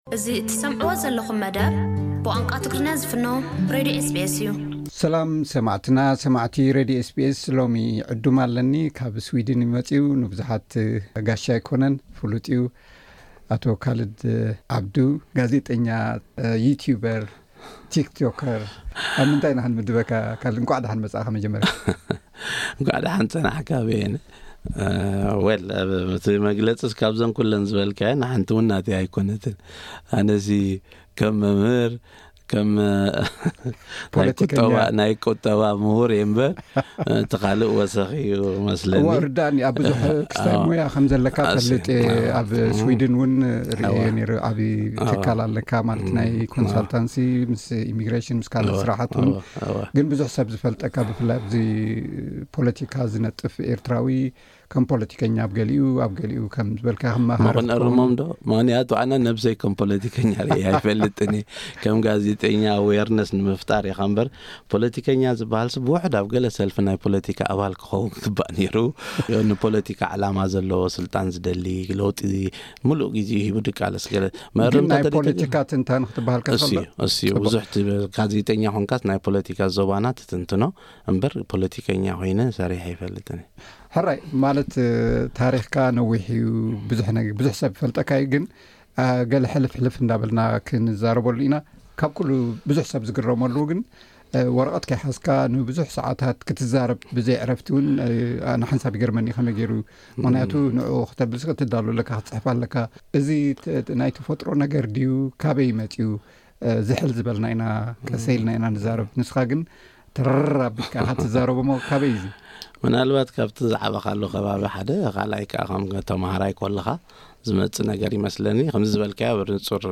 ኣብ ኣውስትራሊያ ኣብ ዝበጽሓሉ ብዛዕባ ታሪኹን ዘካይዶም ንጥፈታትን ሰፊሕ ዕላል ኣካይድናሉ ኣለና። ኣብዚ ናይ ሎሚ ቀዳማይ ክፋል ዕላል ኣብ ኣስመራ ኣብዝነበረሉ እውዋን ብፍላይ ድማ ምስ ብሕታውያን ጋዜጣታት ዝተኣሳሰር ምስ ዘሕለፎ ኩነታት ኣልዒሉ ኣሎ። ኣብ ዝቕጽል መደባት፡ ምስ መንነቱ ዝተኣሳሰር ዝገጥሞ ብድሆታትን ዝህቦ ምላሽን ገሊጹ ኣሎ።